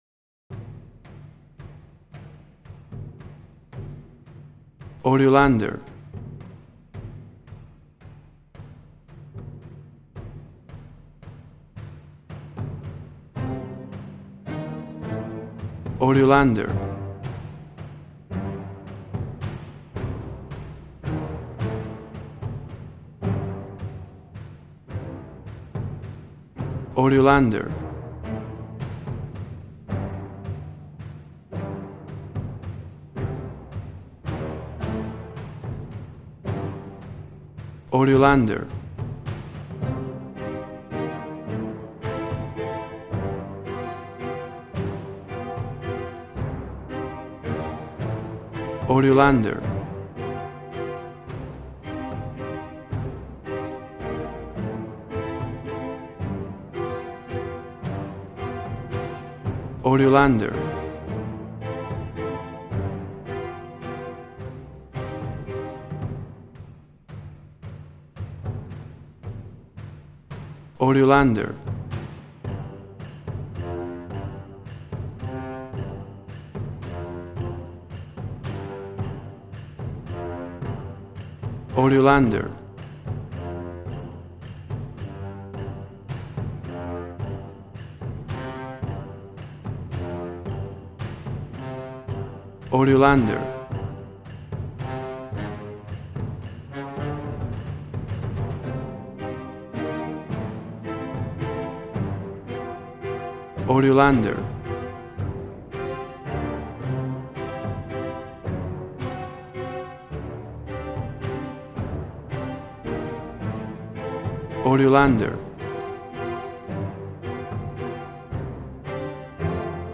Tempo (BPM): 110